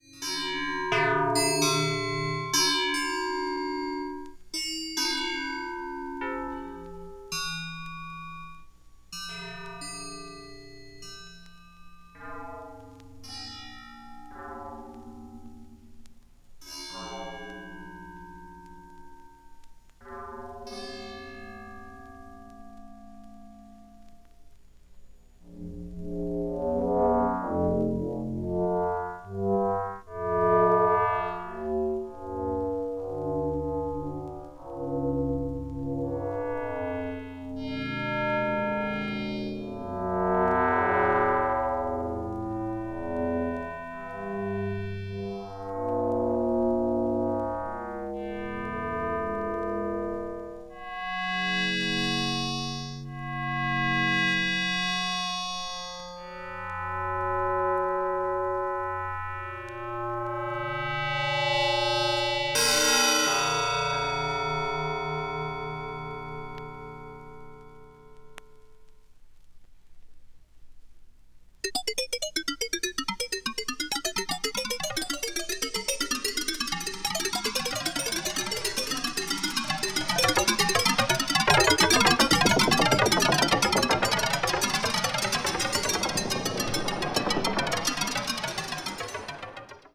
media : VG+/VG+(薄いスリキズによるわずかなチリノイズ/一部軽いチリノイズが入る箇所あり)
20th century   contemporary   electronic   experimental